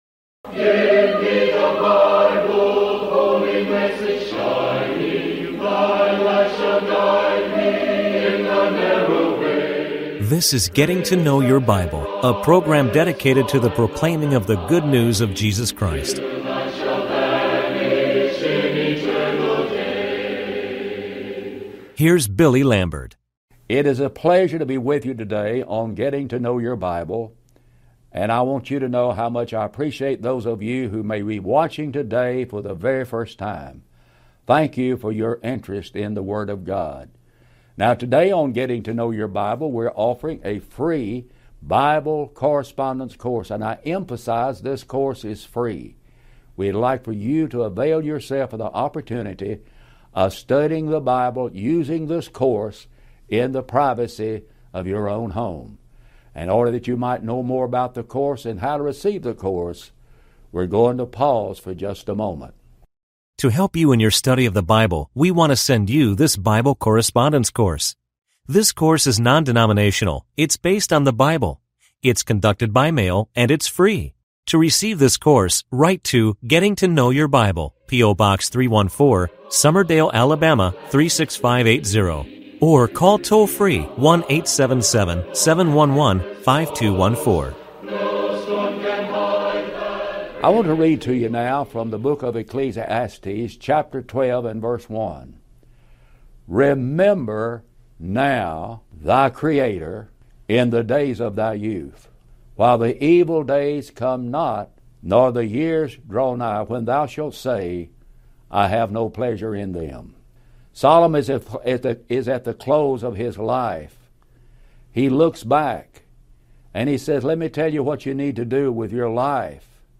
Getting To Know Your Bible is a TV program presented by churches of Christ, who are dedicated to preaching a message of hope and encouragement.